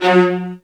VIOLINS.G#-R.wav